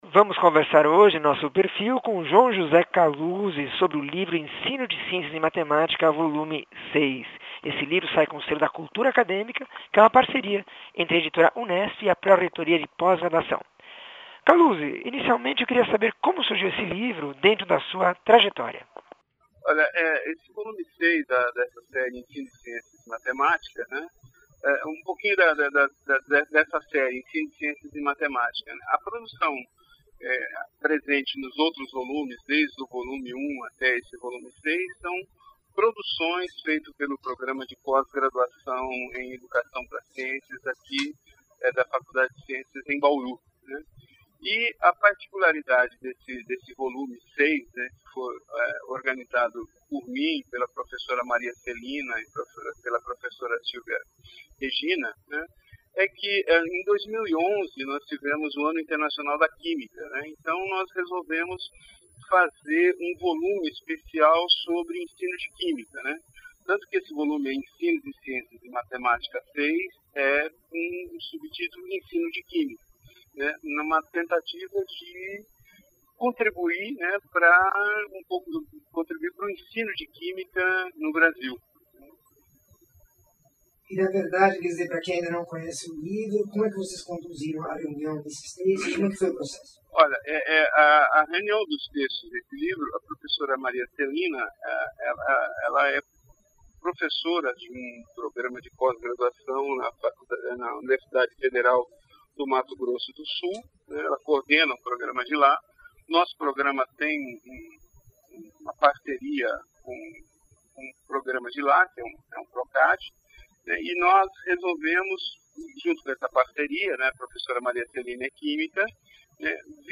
entrevista 1436